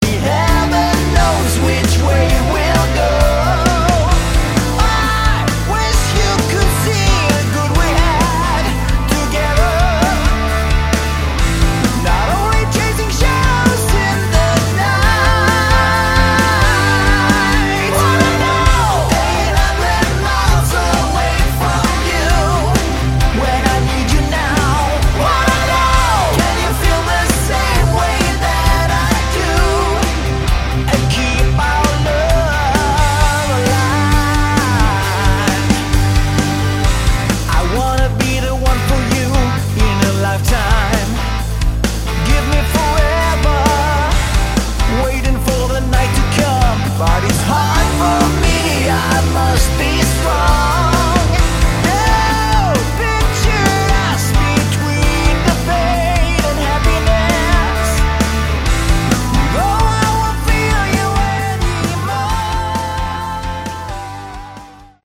Category: Melodic Rock
guitars, keyboards, bass
vocals